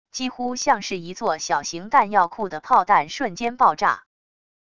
几乎像是一座小型弹药库的炮弹瞬间爆炸wav音频